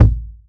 Wu-RZA-Kick 65.wav